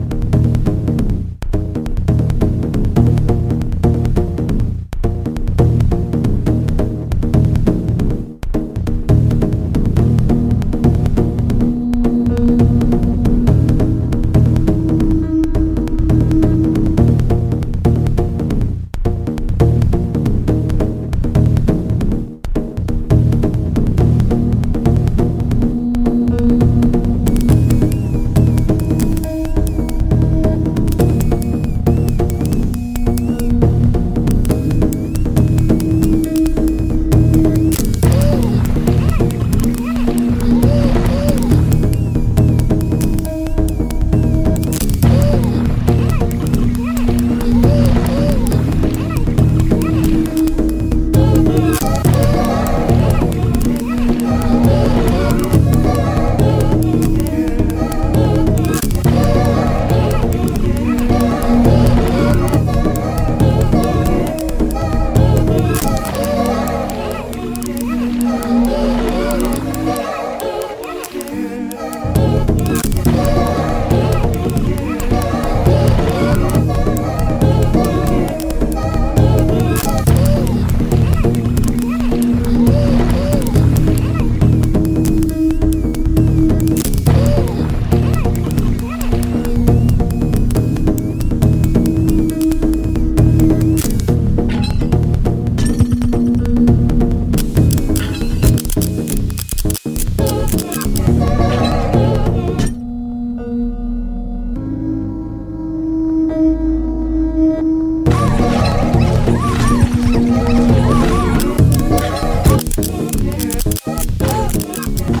House Soul Hip Hop